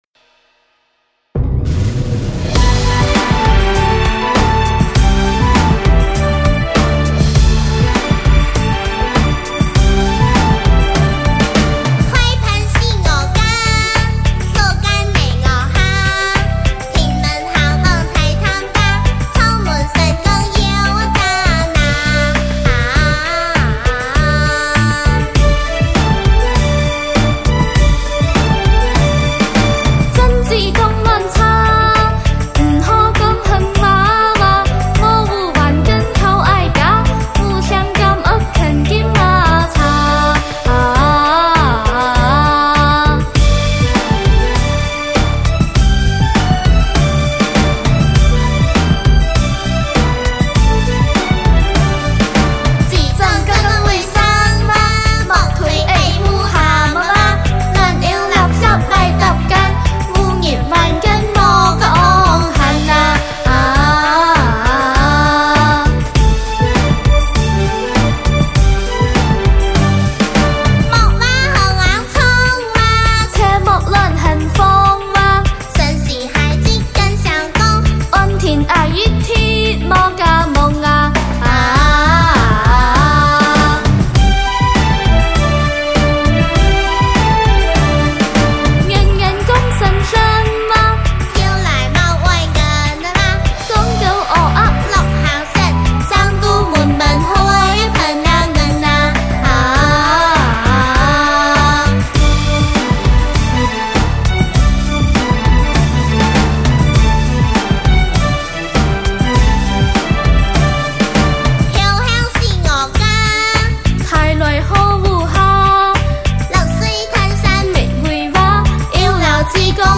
开平民歌集